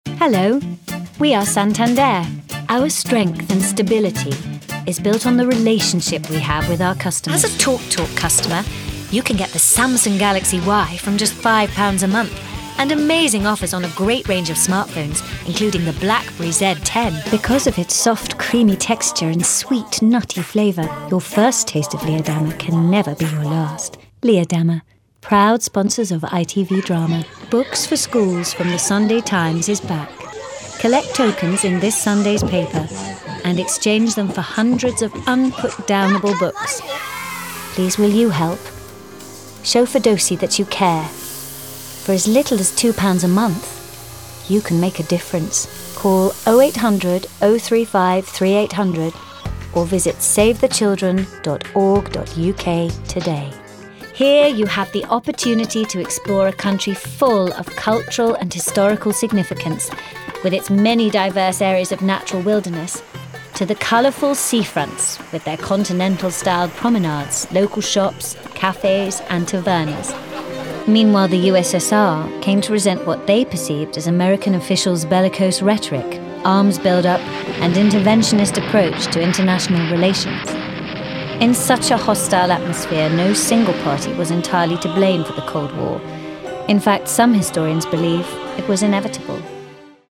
RP. Versatile characters, many accents & standard English. Bright, young, upbeat ads, also a singer.